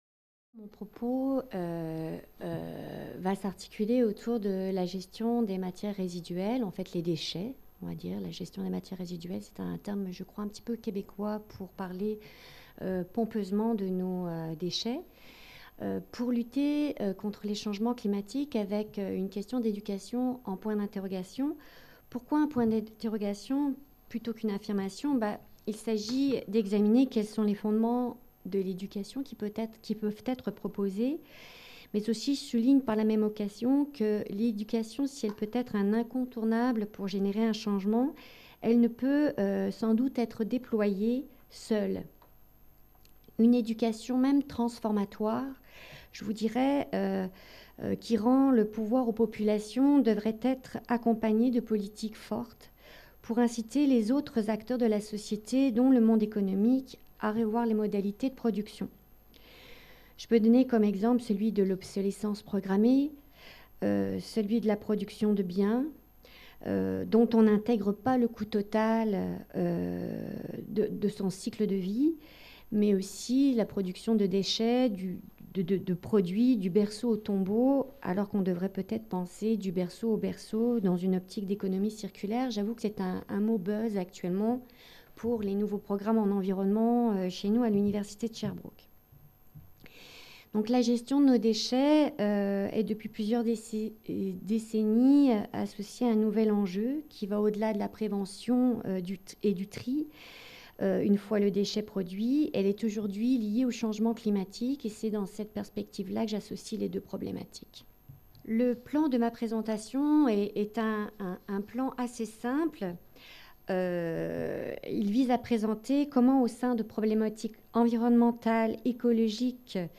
Cette communication a été filmée lors de la série d'événements organisé par la MRSH autour de la question des déchets, abordant les enjeux écologiques qui se posent à nos sociétés.